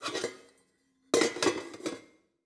SFX_Cooking_Pot_02_Reverb.wav